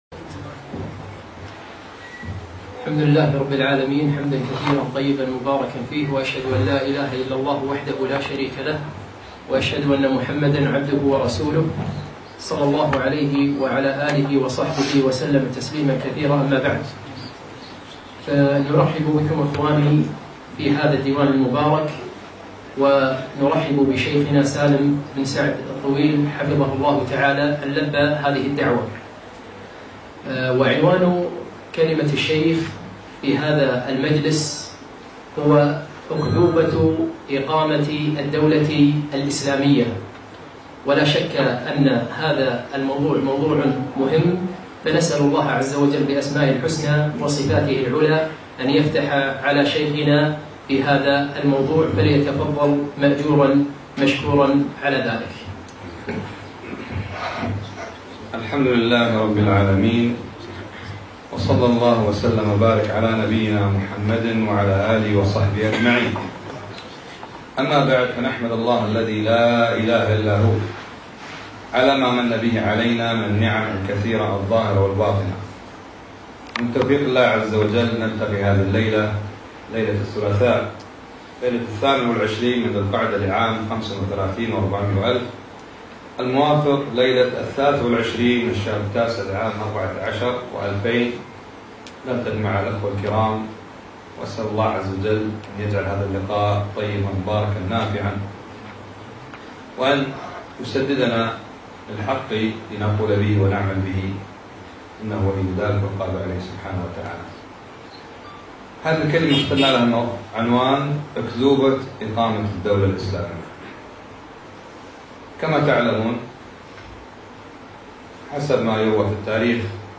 ألقيت المحاضرة في ديوان مشروع الهداية